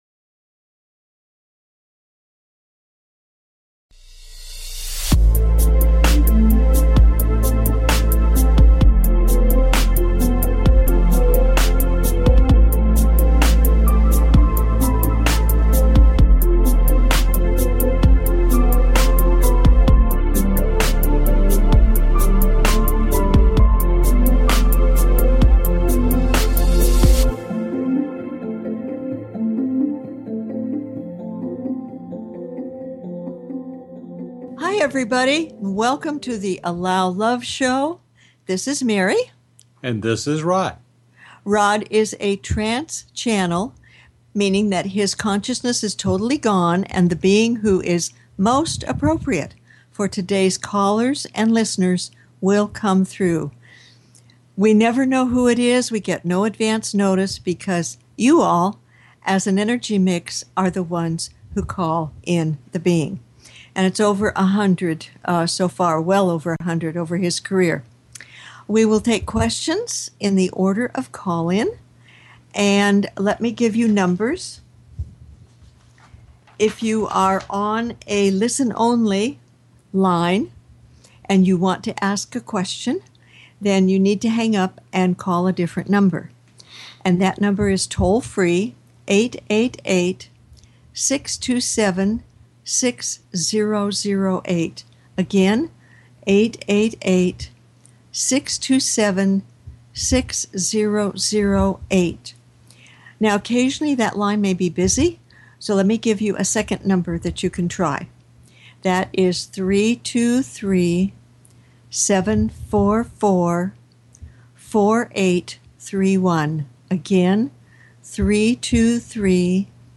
Talk Show Episode, Audio Podcast, The Allow Love Show and with Goddess of Abundance Lakshmi on , show guests , about Goddess of Abundance,Lakshmi, categorized as Paranormal,Ghosts,Philosophy,Spiritual,Access Consciousness,Medium & Channeling